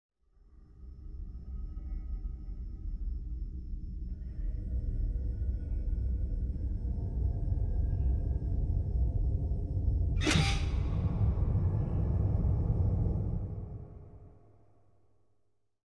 creepy-voice